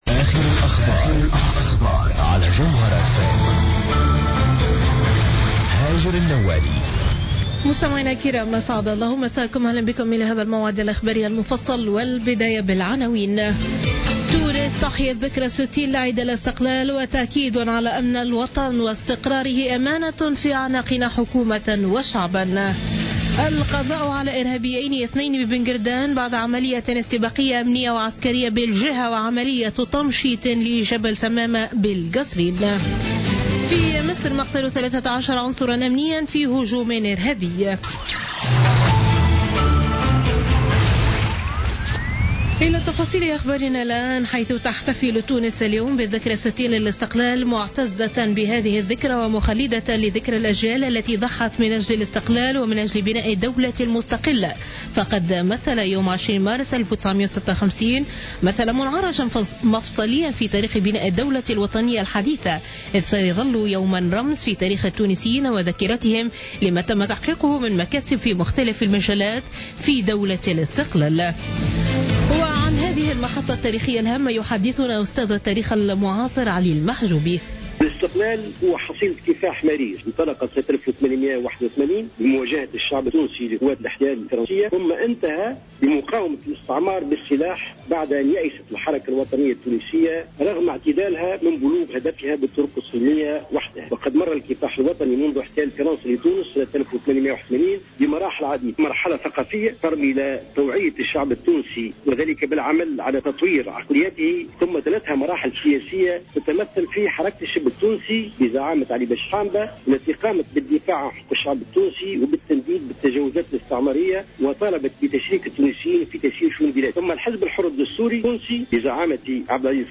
نشرة أخبار منتصف الليل ليوم الأحد 20 مارس 2016